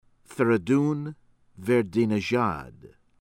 VELAYATI, ALI AKBAR ah-LEE     ahk-BAHR   veh-lah-yah-TEE